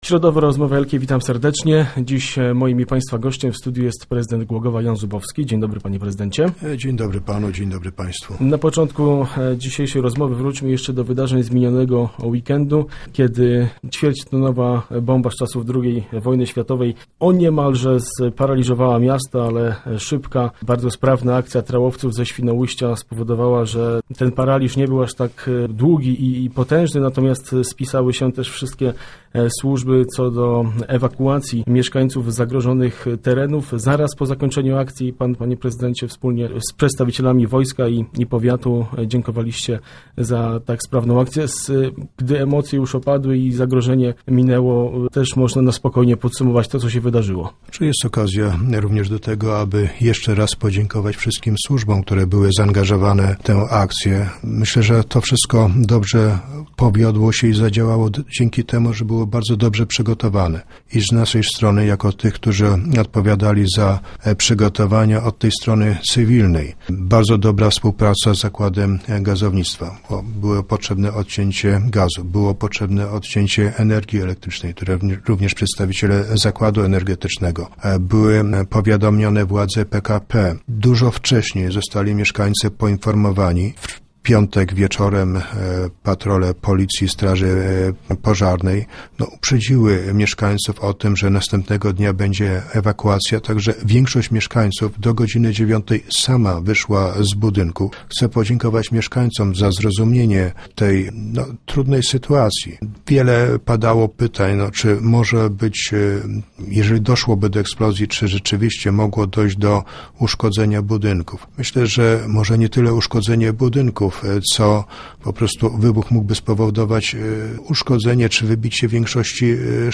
Gmina miejska Głogów rozliczyła finanse za ubiegły rok. Samorząd zakończył rok z mniejszym niż zakładano deficytem budżetowym. Między innymi ten temat poruszaliśmy podczas Rozmów Elki z prezydentem miasta Janem Zubowskim.